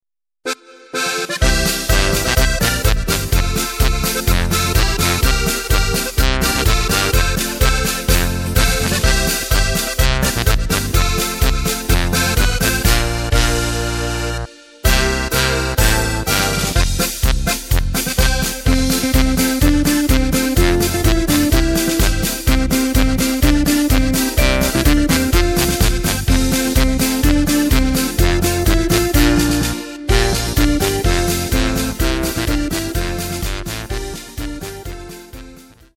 Takt:          2/4
Tempo:         120.00
Tonart:            F
Polka aus dem Jahr 2013!